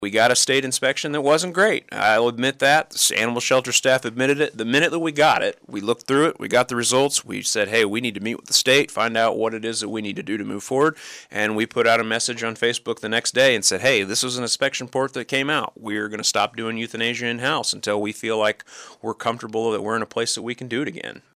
City Manager Jacob Wood joined in on the KSAL Morning News Extra with a look at a range of issues that included a deep dive on how the shelter received an unsatisfactory grade during a January 12th visit from KDA.